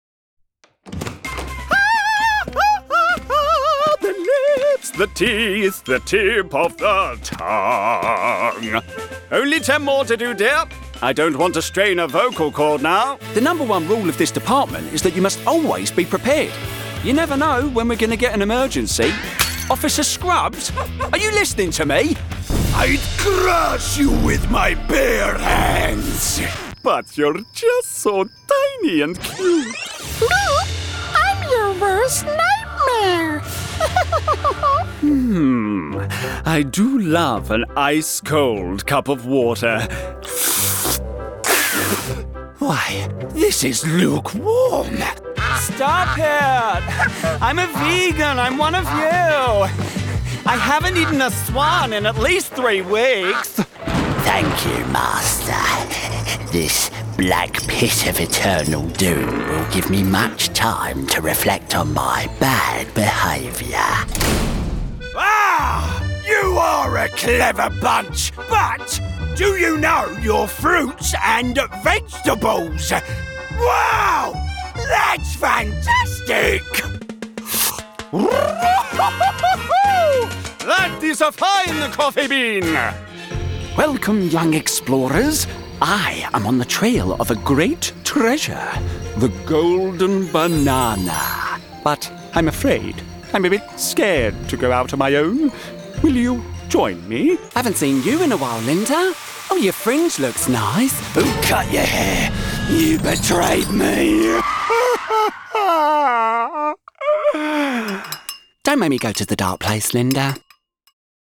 Englisch (Britisch)
Kommerziell, Natürlich, Vielseitig, Warm, Corporate
Persönlichkeiten